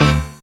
37hp01syn-d.wav